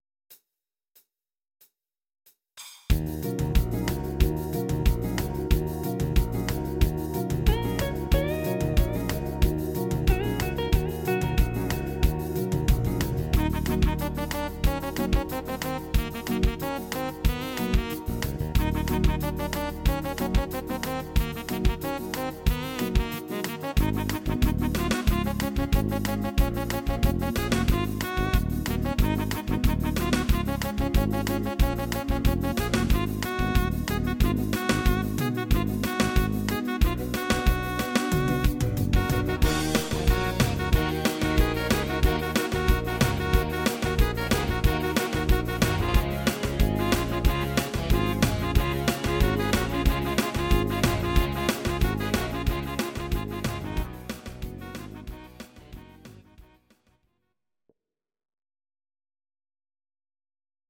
Audio Recordings based on Midi-files
Pop, Ital/French/Span, 2000s